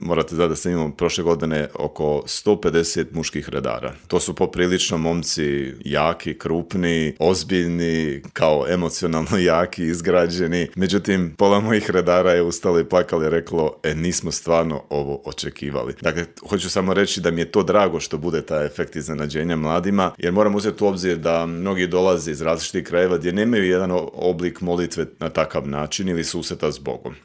Cijeli intervju možete pronaći na YouTube kanalu Media servisa.